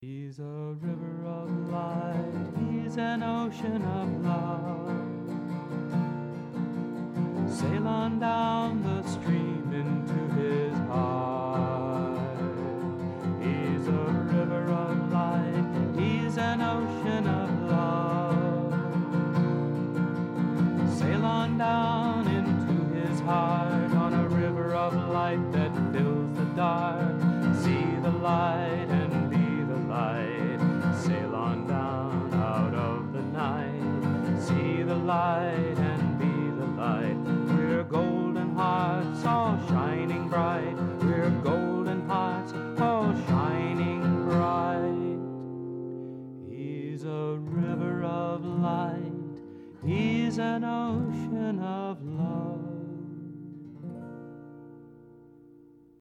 1. Devotional Songs
~ Major (Shankarabharanam / Bilawal)
8 Beat / Keherwa / Adi
Lowest Note: n2 / B (lower octave)
Highest Note: S / C (higher octave)